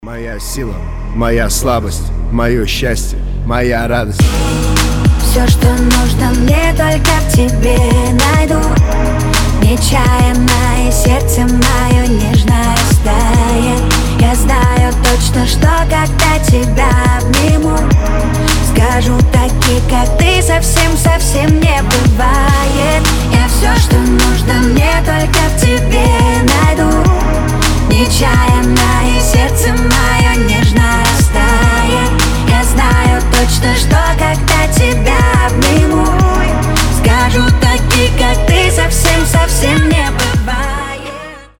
• Качество: 320, Stereo
поп
мужской голос
женский вокал